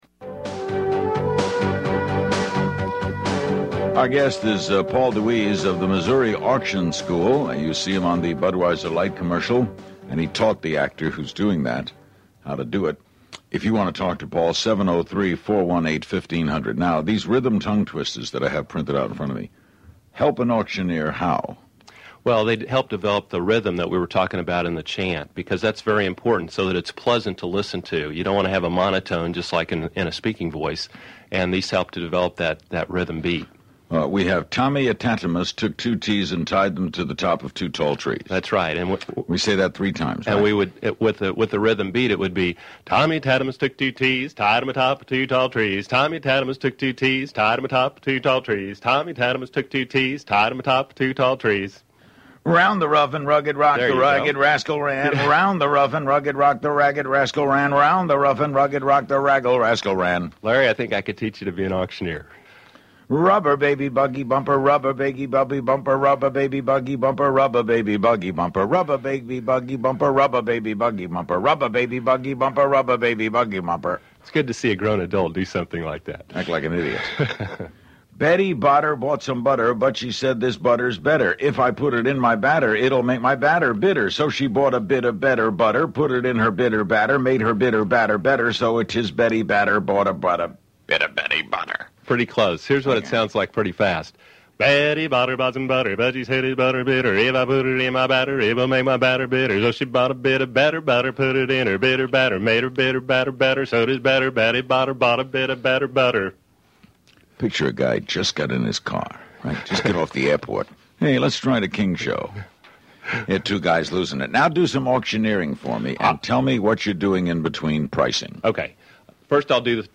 The Larry King Interview, Part 2